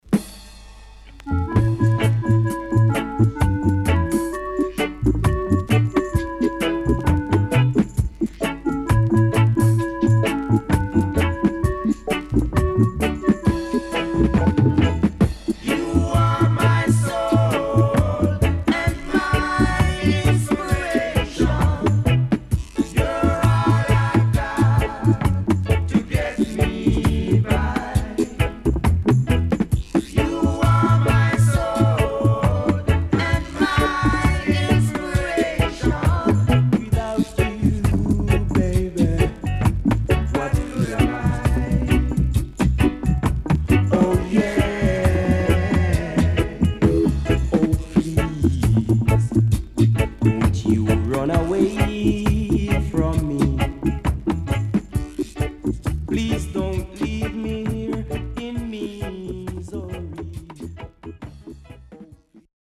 CONDITION SIDE A:VG(OK)〜VG+
SIDE A:所々チリノイズがあり、少しプチノイズ入ります。